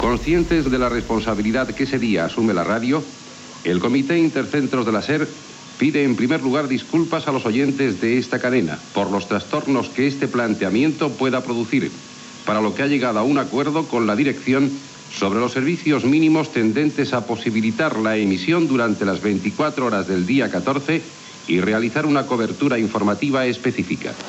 Comunicat del comitè intercentres de la Cadena SER amb motiu de la vaga general de treballadors
Informatiu